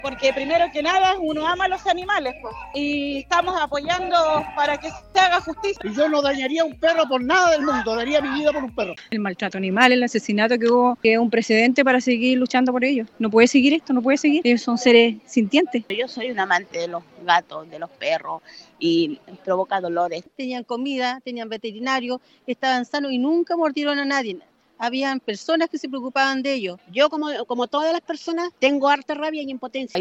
Varios de los asistentes acudieron con sus propias mascotas. En conversación con La Radio se mostraron manifestaron su postura en contra del maltrato animal.